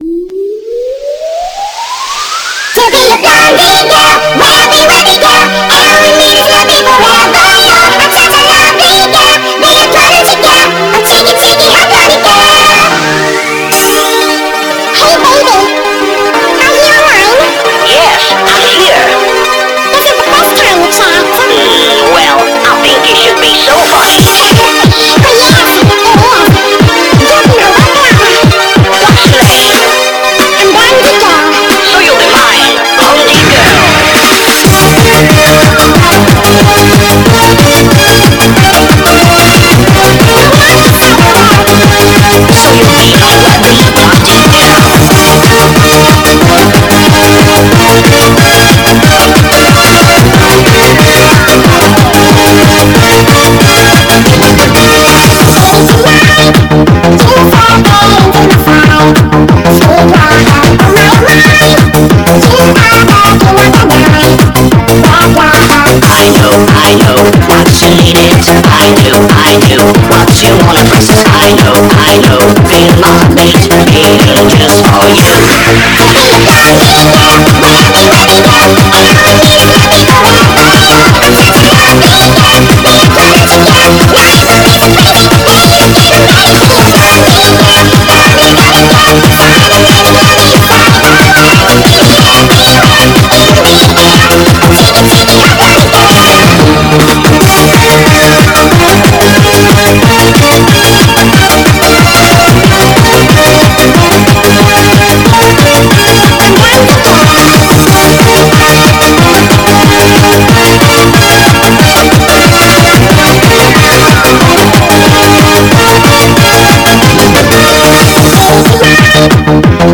Nightcore